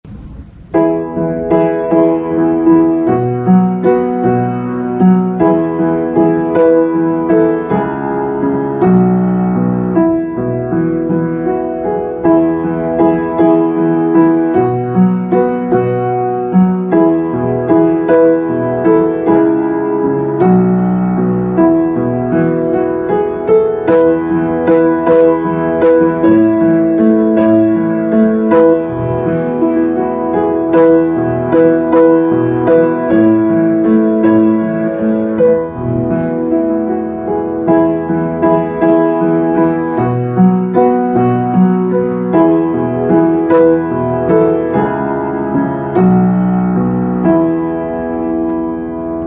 45-Piano2.mp3